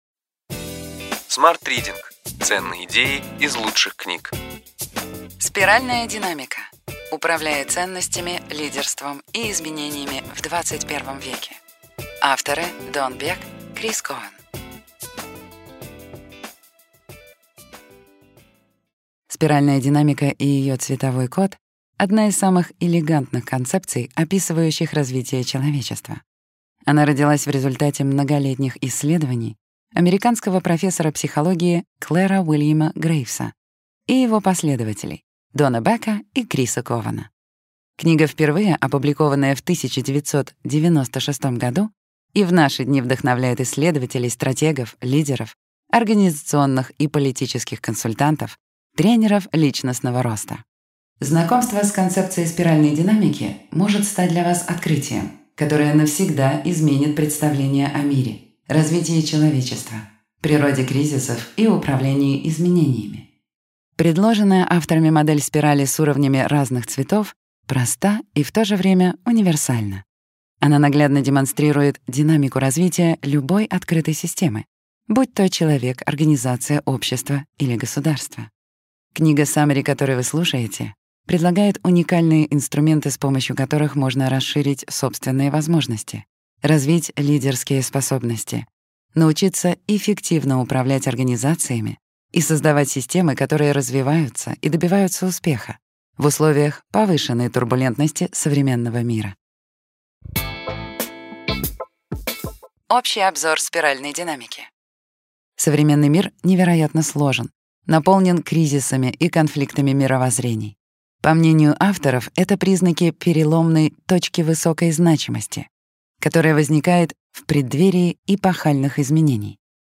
Аудиокнига Ключевые идеи книги: Спиральная динамика. Управляя ценностями, лидерством и изменениями в XXI веке.